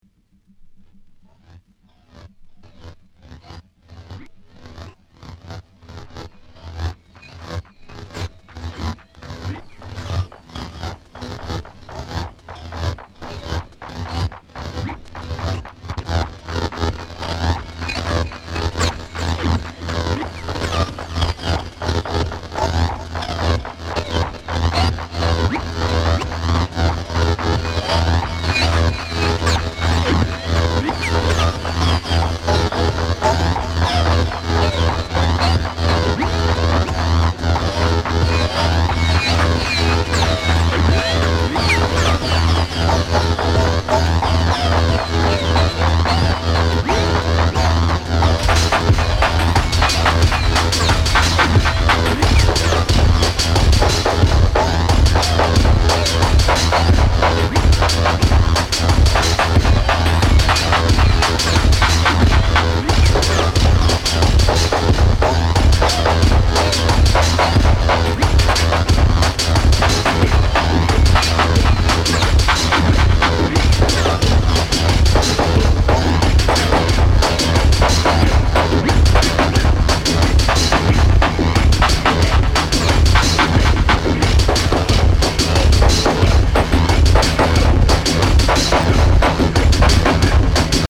LP]リズミック・ノイズテクノミニマル